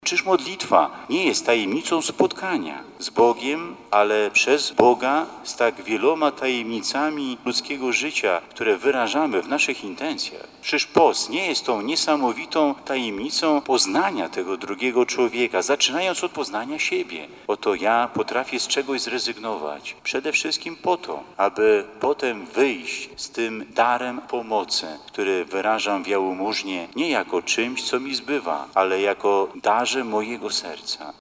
Centralne uroczystości z okazji 25. Światowego Dnia Chorego w diecezji warszawsko-praskiej odbyły się w sanktuarium Matki Bożej z Lourdes.
Kaznodzieja zwrócił uwagę, że poprzez wierność Bogu człowiek niesie pomoc innym.